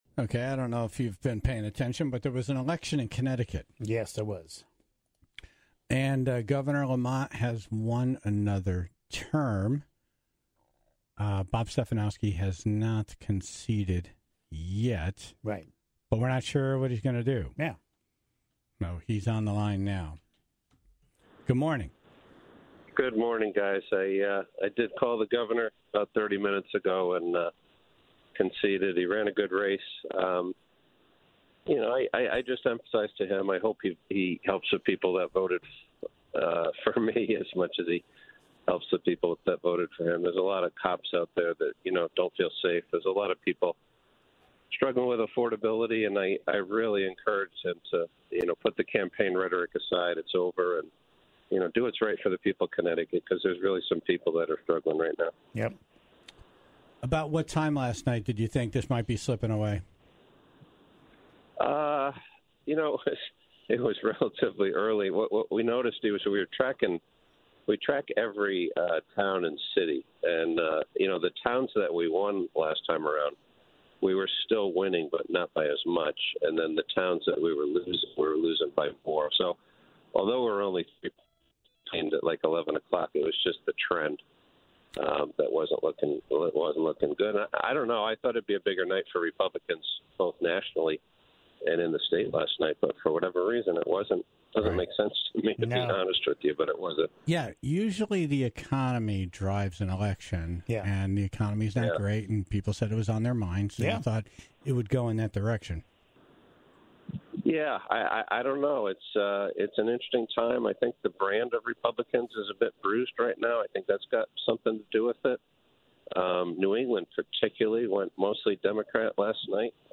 Plus, Attorney General William Tong called in from a tattered Hartford hotel room, after celebrating his victory.